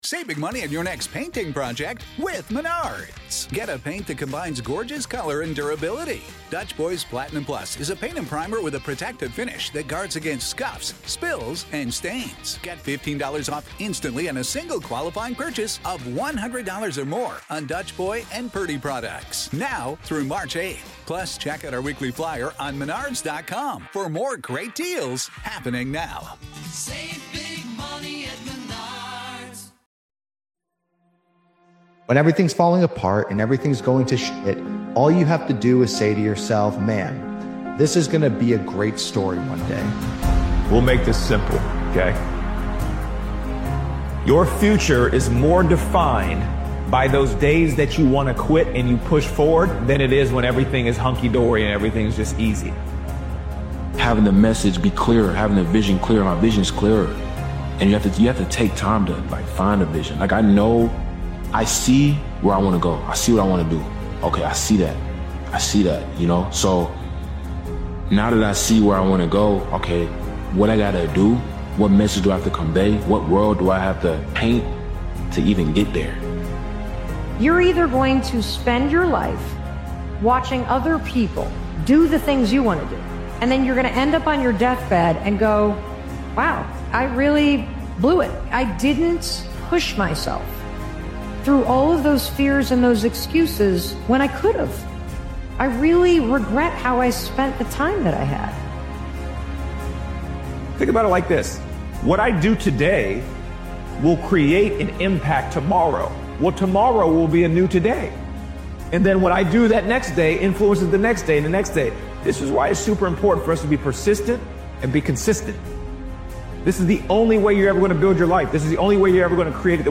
Powerful Motivational Speech is an empowering and confidence-building motivational speech created and edited by Daily Motivations. This powerful motivational speech compilation is a reminder that self-belief is the starting point of every breakthrough. Doubt delays progress, but belief activates it.